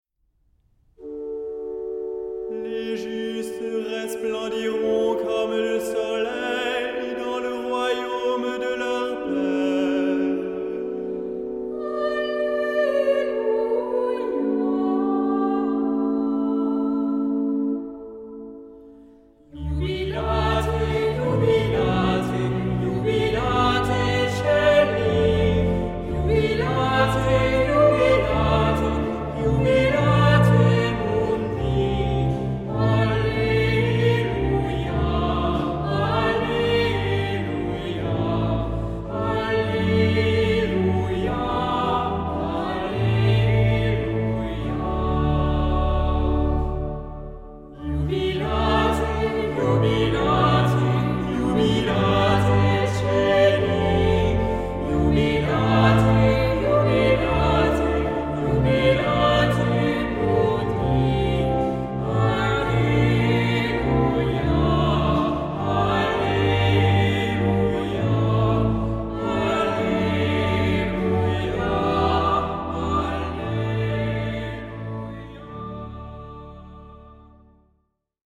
Genre-Stil-Form: Tropar ; Psalmodie ; geistlich
Charakter des Stückes: andächtig
Chorgattung: SATB  (4 gemischter Chor Stimmen )
Instrumente: Orgel (1)
Tonart(en): D-Dur